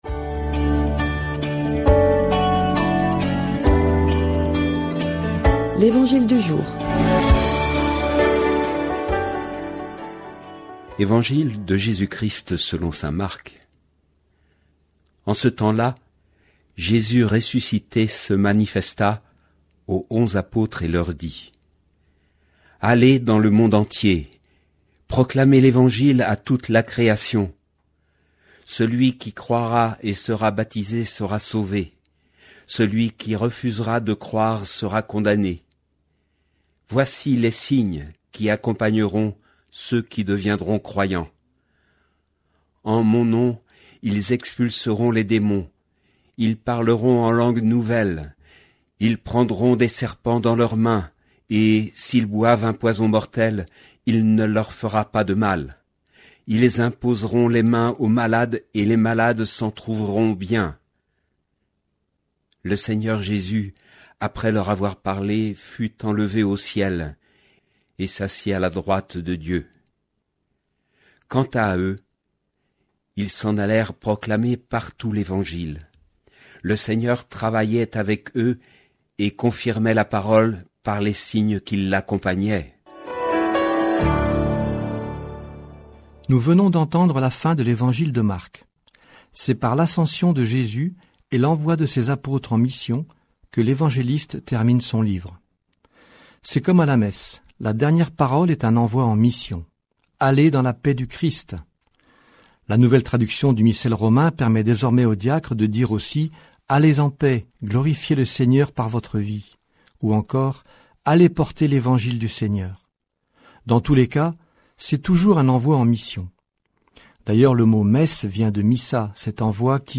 Commentaire d'évangile
Commentaire diffusé sur Radio Fidélité, radio chrétienne de Nantes.